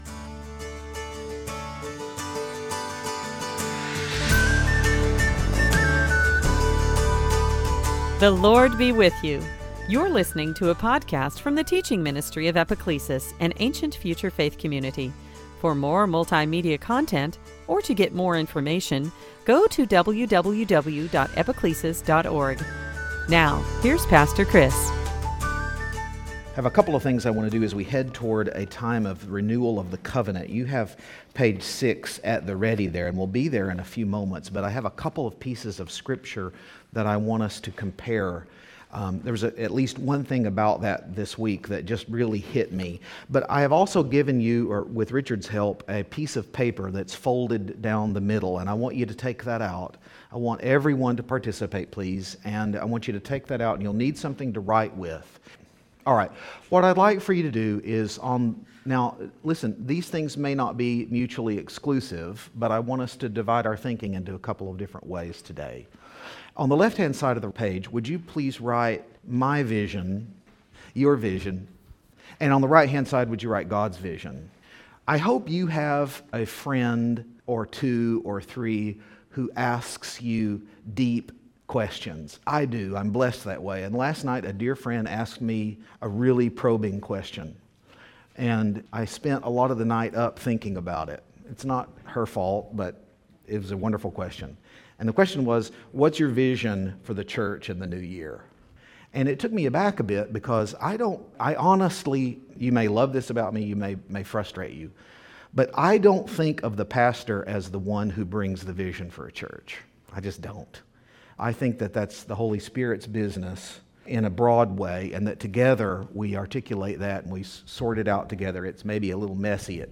Series: Sunday Teaching At Epiclesis
At Epiclesis, we like to start the new year with a covenant renewal service, and this January 1st Sunday was no exception. But before we got to the declarations and oaths-- and you really need to hear them-- we looked at two important scenes in the life of the Children of Israel: The covenant at Sinai, and the return from Babylonian Captivity.